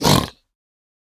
assets / minecraft / sounds / mob / piglin / hurt3.ogg
hurt3.ogg